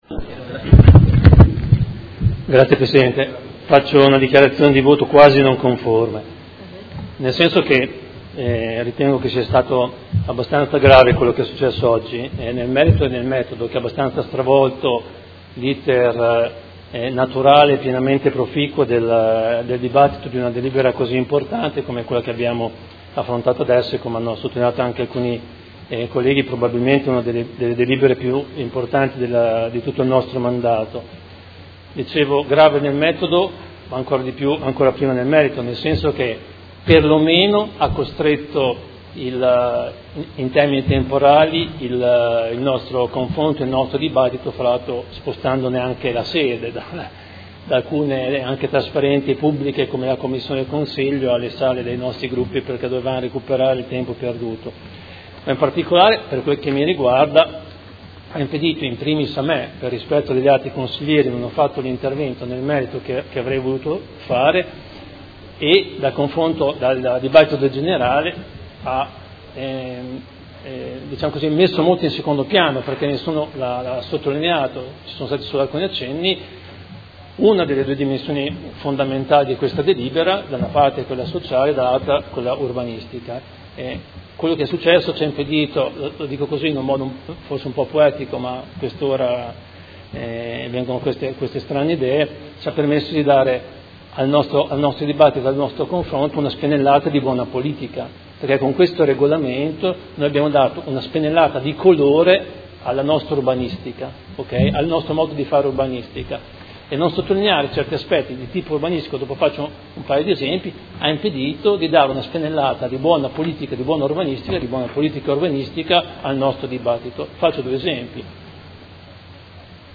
Seduta del 17/05/2018. Dichiarazioni di voto su proposta di deliberazione: Regolamento Edilizia convenzionata e agevolata - Approvazione, emendamenti e Ordine del Giorno presentato dal Gruppo Consigliare PD avente per oggetto: Rafforzamento delle politiche pubbliche per l'accesso alla casa attraverso il nuovo regolamento per l'edilizia convenzionata e agevolata